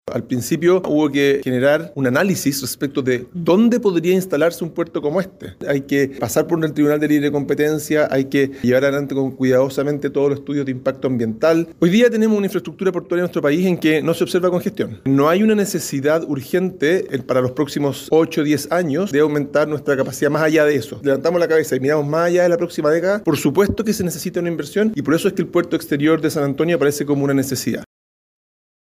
El ministro de Transporte, Juan Carlos Muñoz, afirmó que no considera que hoy sea una necesidad urgente el comenzar las operaciones del Puerto Exterior.